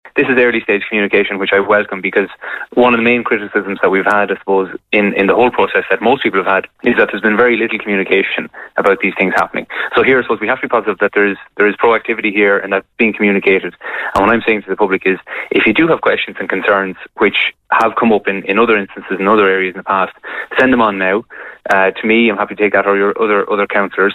Fianna Fail Cllr. Rob Power had this to say on this morning’s ‘Kildare Today’.